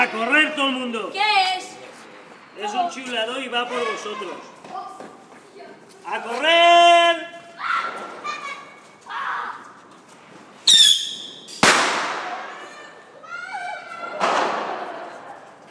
Petardo silbador